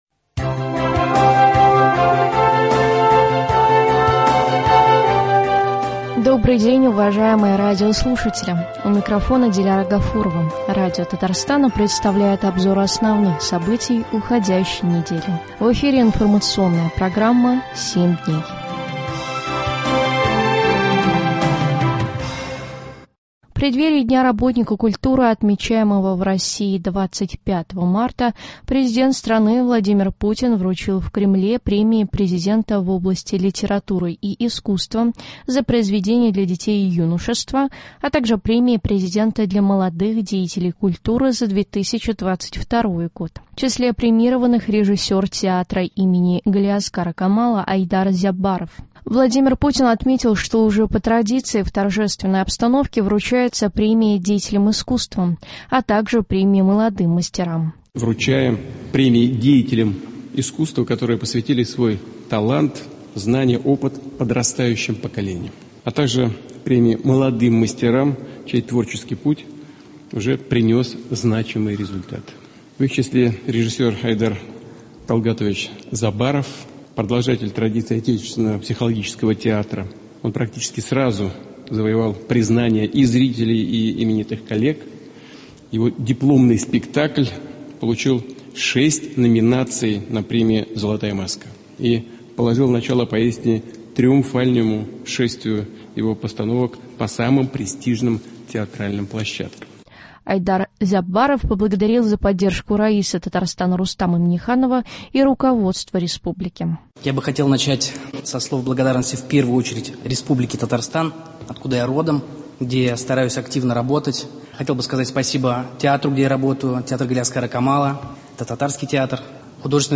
Обзор событий.